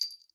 tbd-station-14/Resources/Audio/Effects/Footsteps/jesterstep2.ogg at d1661c1bf7f75c2a0759c08ed6b901b7b6f3388c